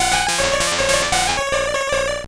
Cri de Lippoutou dans Pokémon Or et Argent.